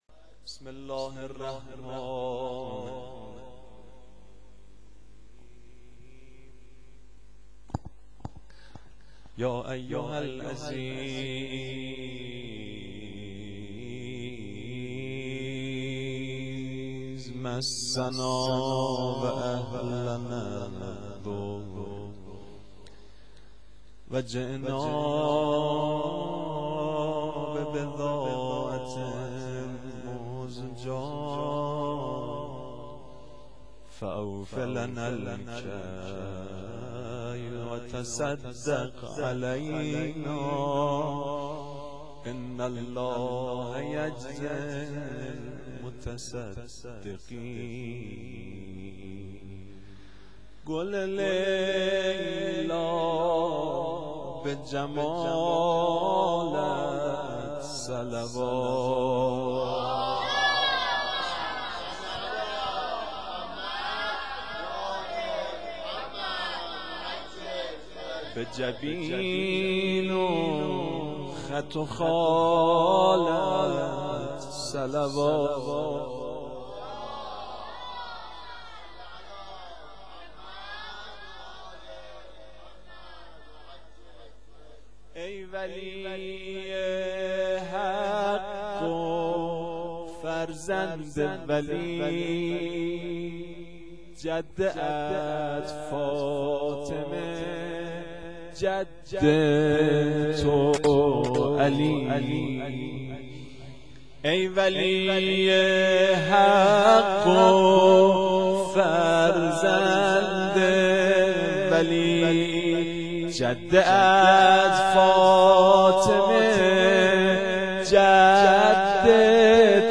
مدیحه سرایی
شام میلاد حضرت علی اکبر